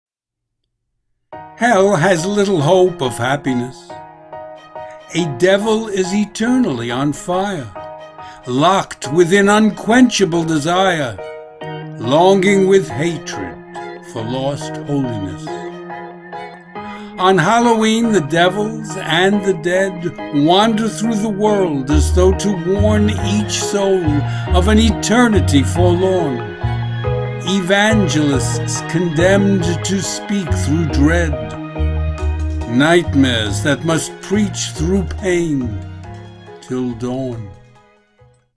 Music: Pieces en Concert -- Prelude, La Tromba, Air de Diable.
By Francois Couperin.
Performed by The Advent Chamber Orchestra at the Free Music Archive